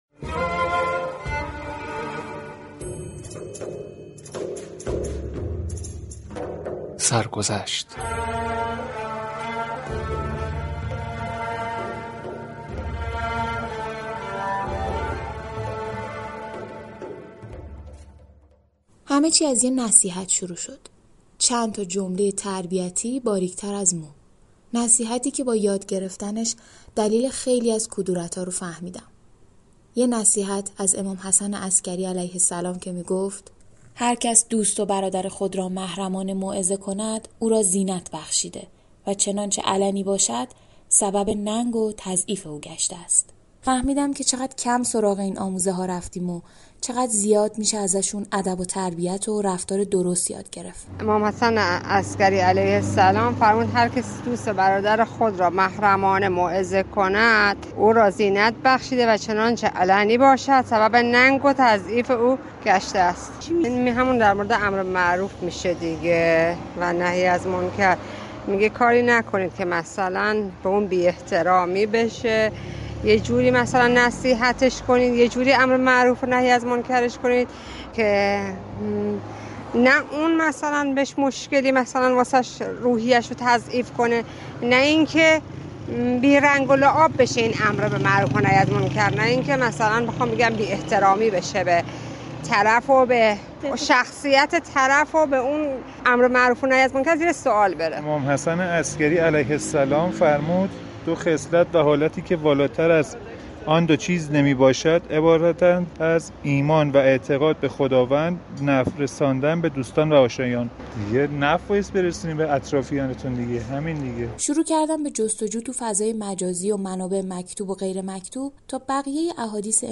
این برنامه مستندی است درباره ی چند حدیث اخلاقی و فرهنگی از امام حسن عسكری(ع) و پاسخ به این سوال كه چگونه فرهنگ و شخصیت داشتن را از آموزه های دینی بیاموزیم؟